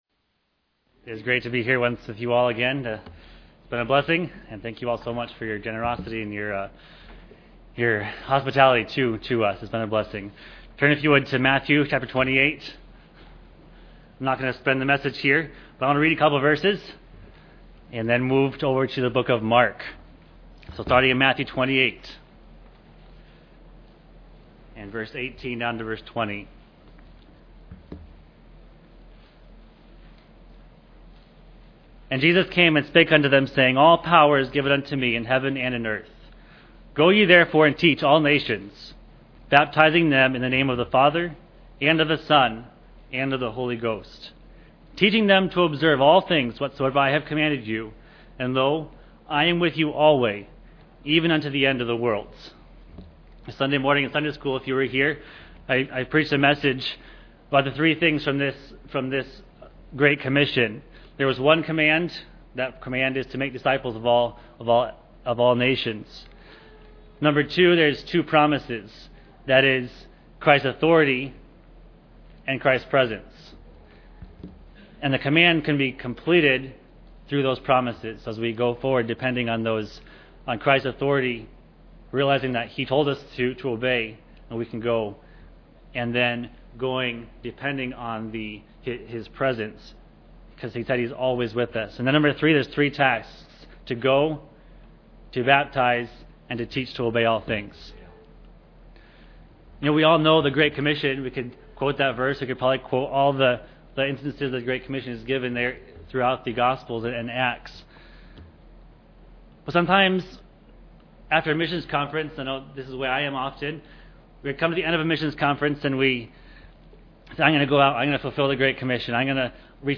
Series: 2017 Missions Conference
Service Type: Special Service